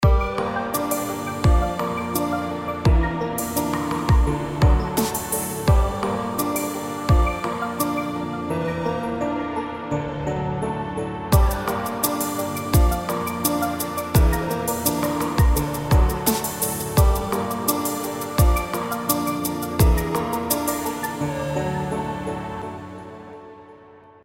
Мистика
chillout?